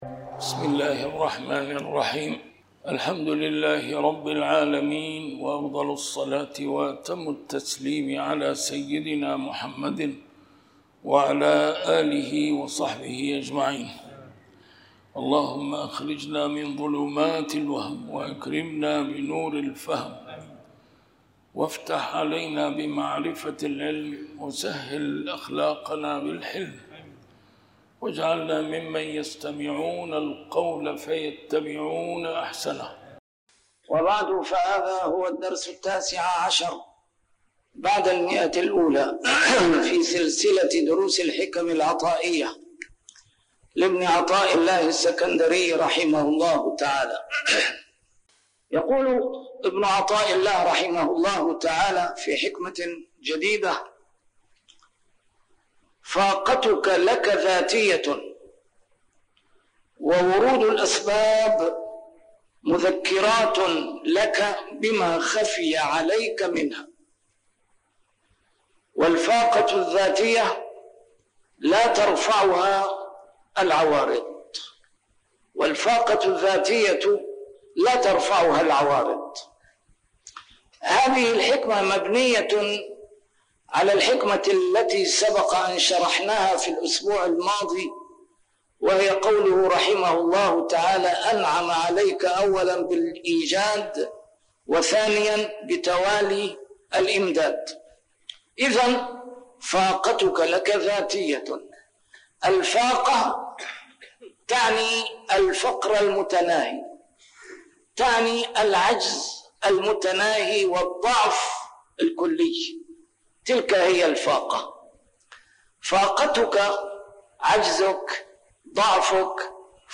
A MARTYR SCHOLAR: IMAM MUHAMMAD SAEED RAMADAN AL-BOUTI - الدروس العلمية - شرح الحكم العطائية - الدرس رقم 119 شرح الحكمة 99